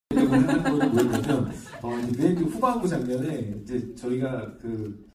Her laugh is contagious 🥹🫶 sound effects free download